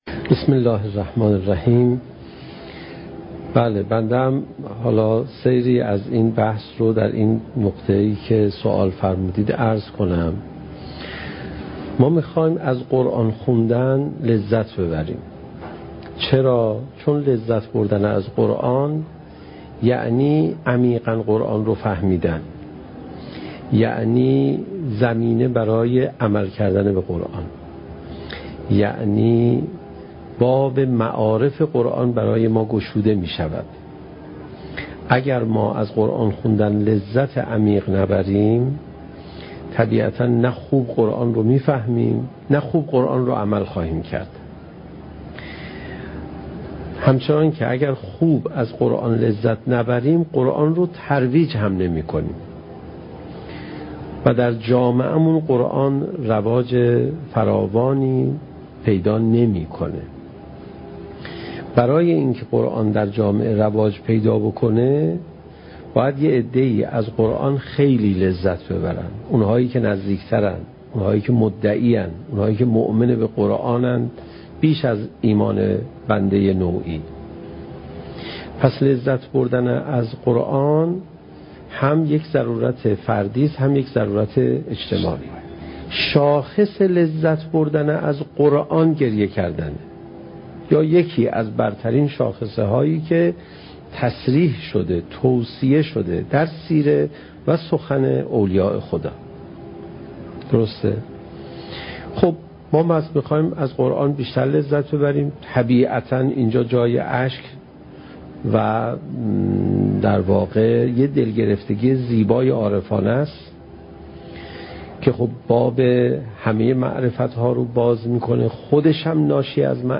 سخنرانی حجت الاسلام علیرضا پناهیان با موضوع "چگونه بهتر قرآن بخوانیم؟"؛ جلسه نهم: "کمال طلبی انسان"